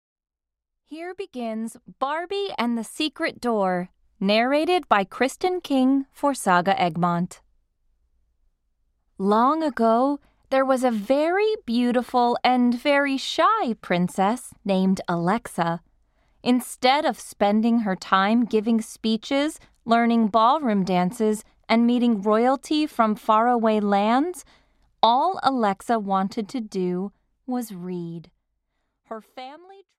Barbie - The Secret Door (EN) audiokniha
Ukázka z knihy